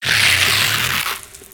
Cri de Solochi dans Pokémon X et Y.